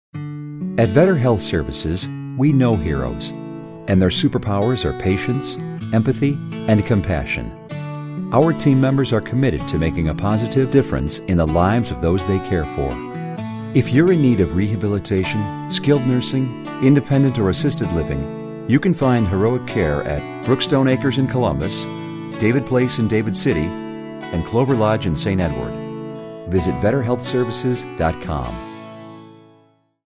VSL Heroes Radio Spot